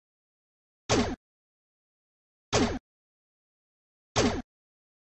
激光命中.ogg